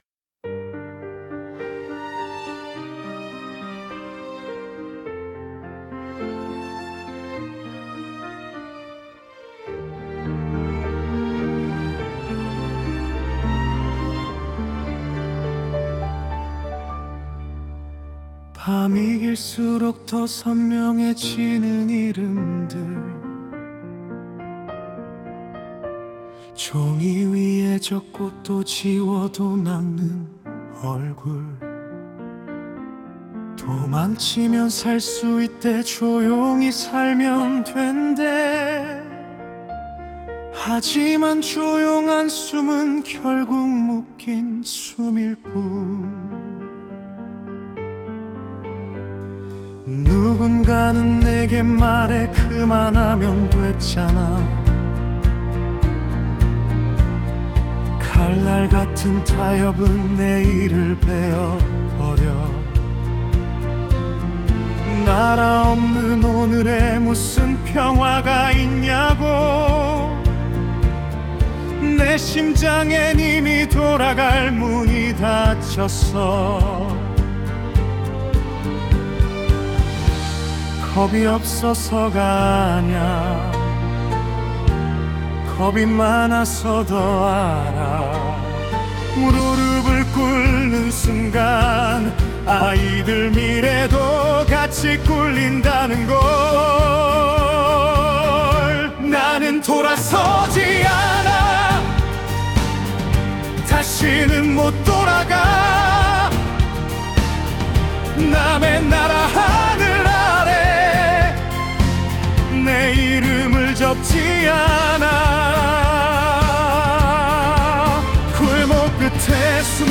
다운로드 설정 정보 Scene (장면) Topic (주제) Suno 생성 가이드 (참고) Style of Music Female Vocals, Soft Voice Lyrics Structure [Meta] Language: Korean Topic: [Verse 1] (조용한 시작, 의 분위기를 묘사함) ...